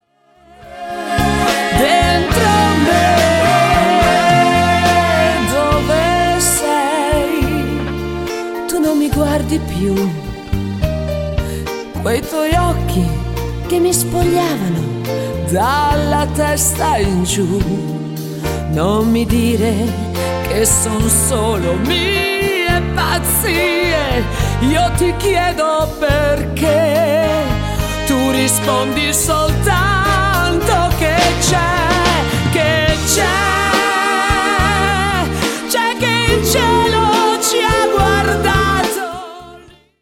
TERZINATO  (4.00)